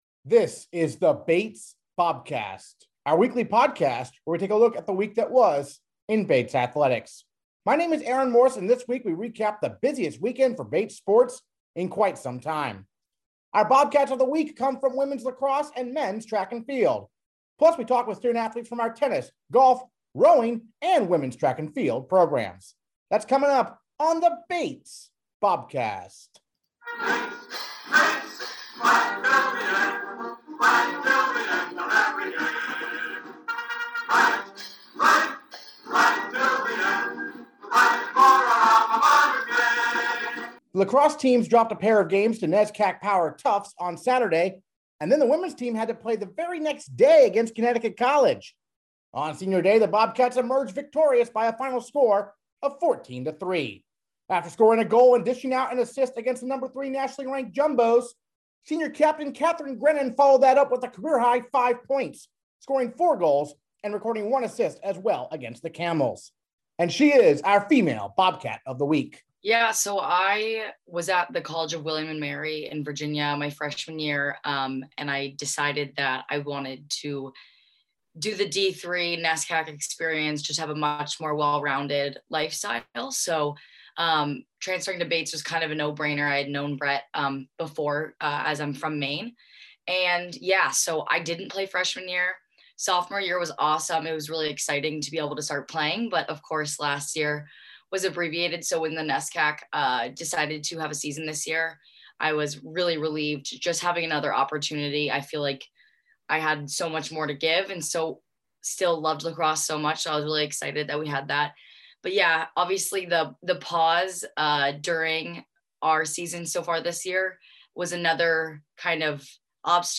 This week we recap the busiest weekend for Bates sports in quite some time! Our Bobcats of the Week come from women's lacrosse and men's track and field. Plus, we talk with student-athletes from our tennis, golf, rowing and women's track and field programs.